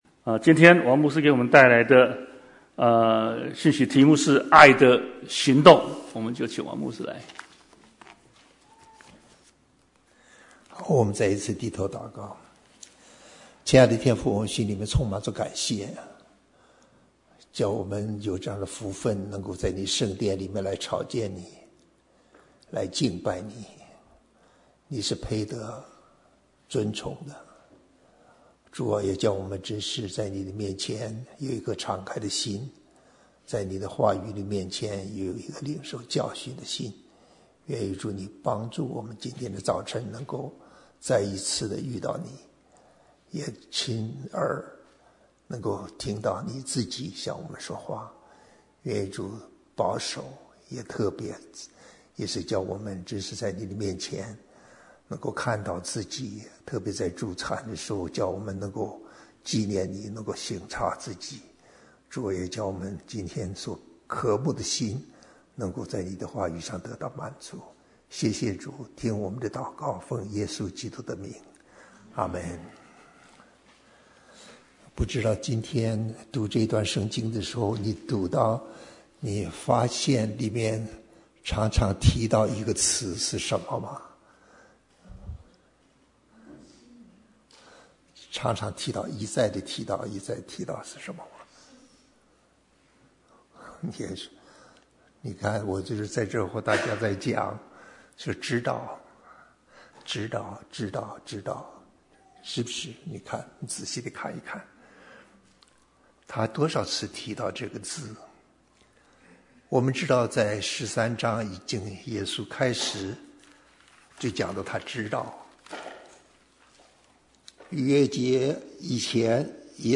Bible Text: 約翰福音 13:1-11 | Preacher